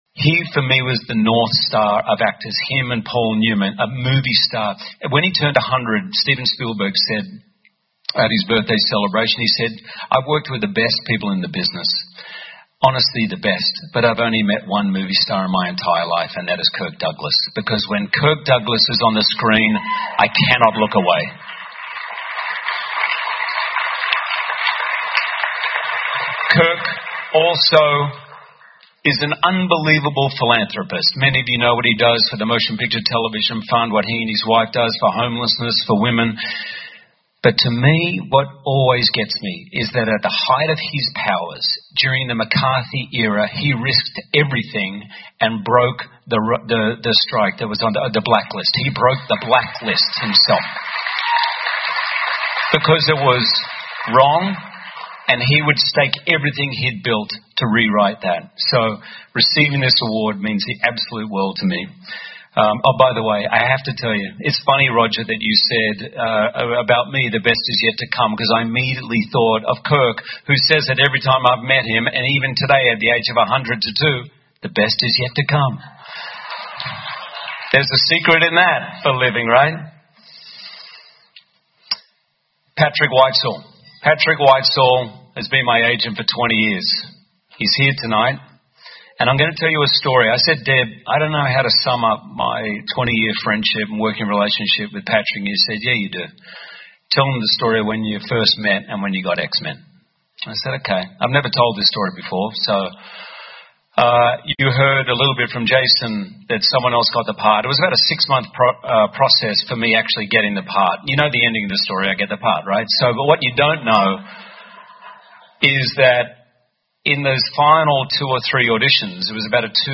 英文演讲录 休·杰克曼：感谢三个很重要的人(2) 听力文件下载—在线英语听力室